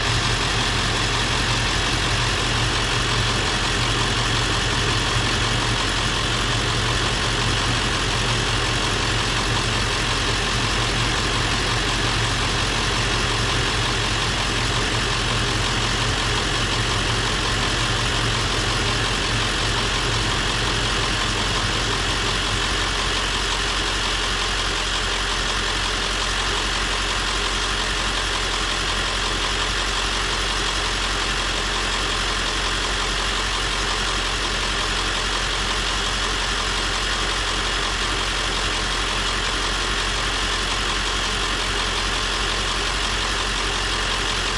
random » bus coach ext diesel truck engine idle throaty
描述：bus coach ext diesel truck engine idle throaty.flac
标签： truck ext idle diesel bus throaty coach engine
声道立体声